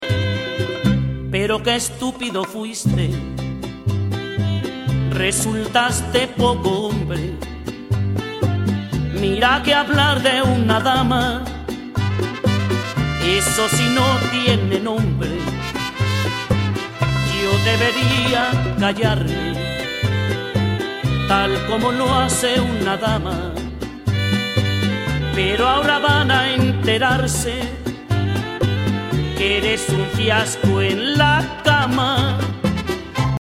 Musica regional Mexicana